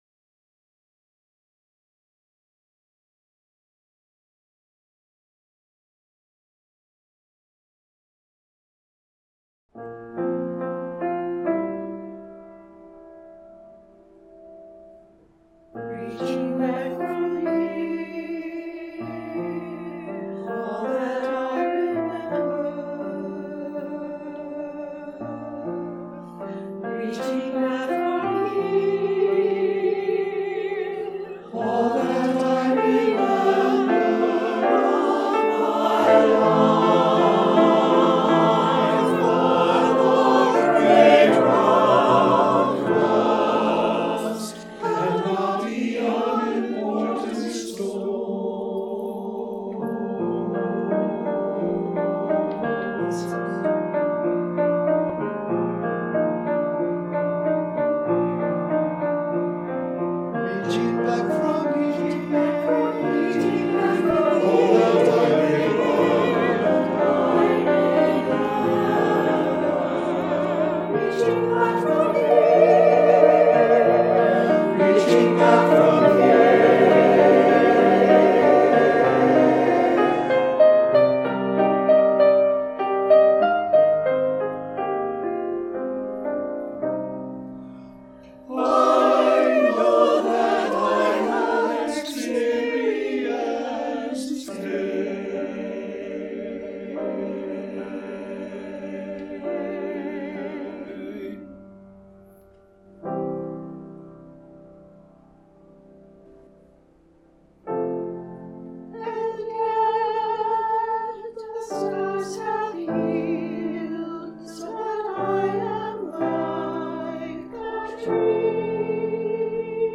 SATB, piano